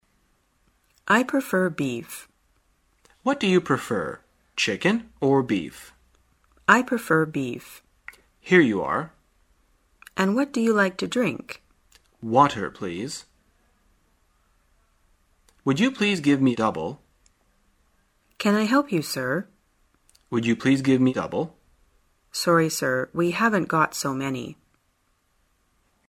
在线英语听力室生活口语天天说 第118期:怎样点菜的听力文件下载,《生活口语天天说》栏目将日常生活中最常用到的口语句型进行收集和重点讲解。真人发音配字幕帮助英语爱好者们练习听力并进行口语跟读。